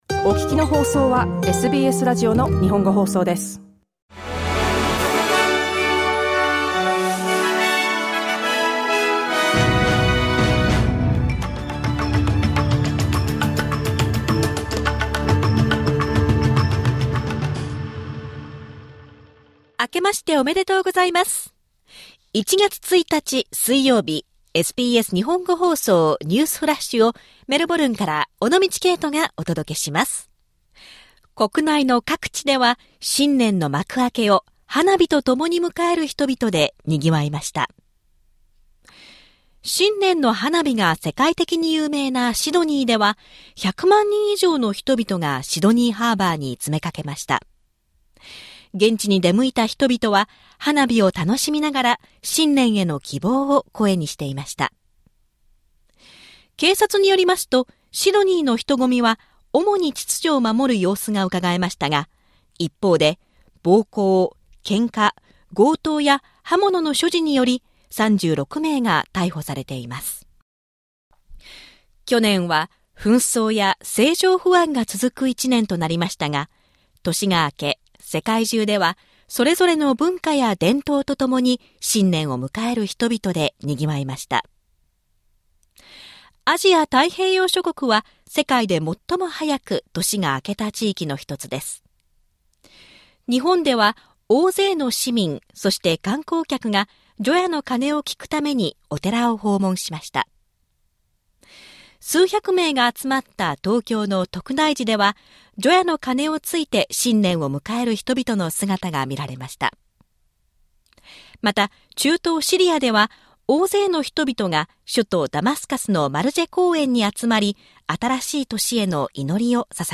SBS日本語放送ニュースフラッシュ 1月1日 水曜日